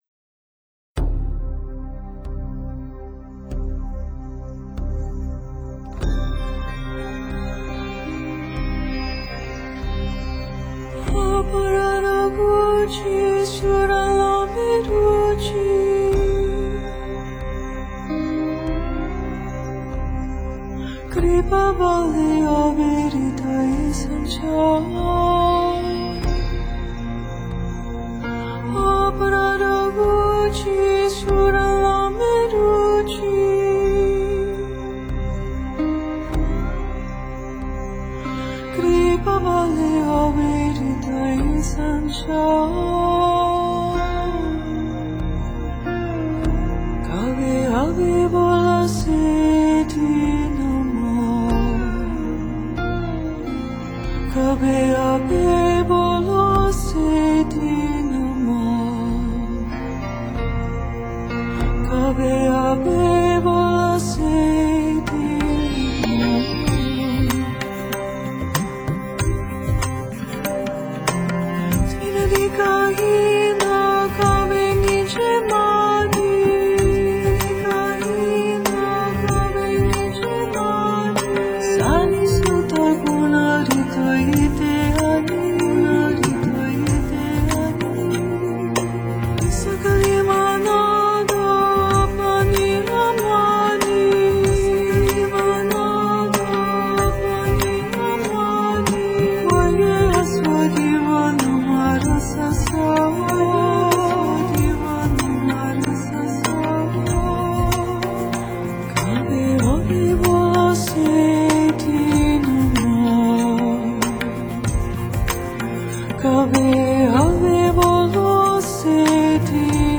合并了印度古代毗湿奴教派的传统歌谣以及现代的音乐编排方式
混合着冥想似的沉默，仙音般的气质，高雅而卓越的声音。